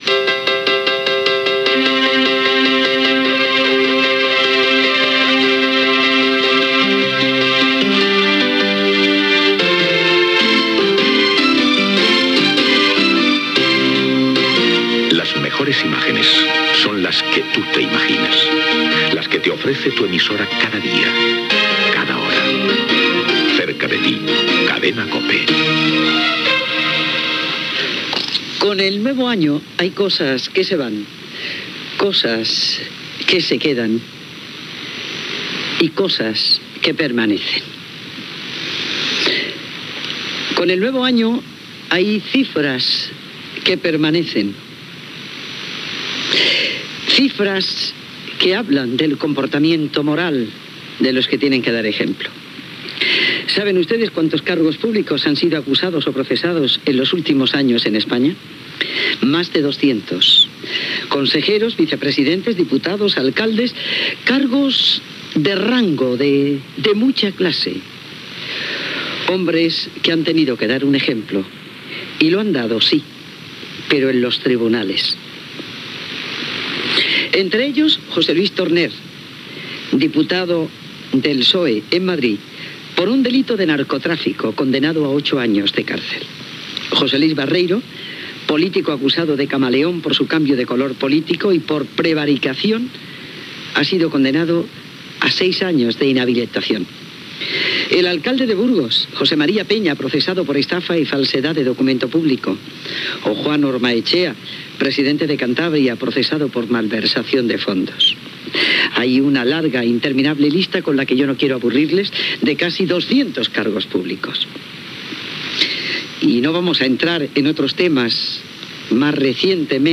Indicatiu de l'emissora, editorial sobre la corrupció dels càrrecs públics espanyols, careta del programa, sumari de continguts i invitats, indicatiu
Info-entreteniment